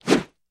Звуки взмахов
Быстрый взмах руки